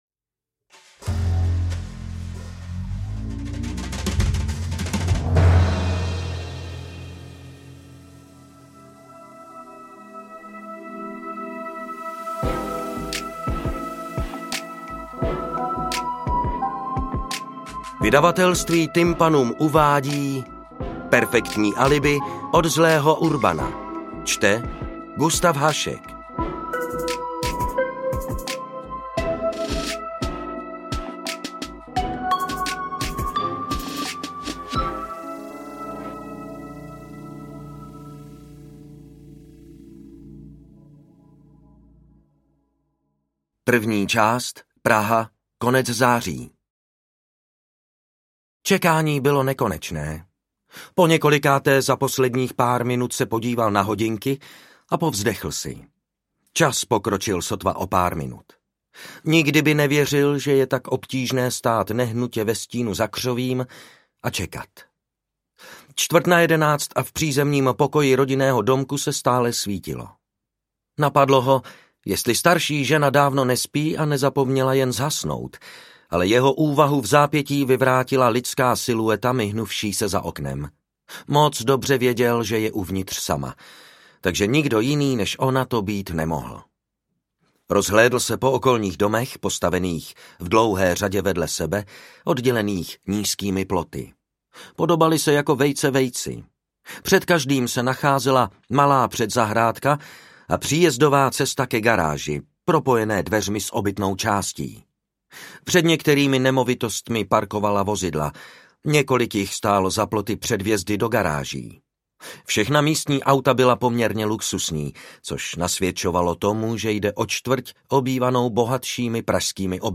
AudioKniha ke stažení, 77 x mp3, délka 10 hod. 47 min., velikost 626,7 MB, česky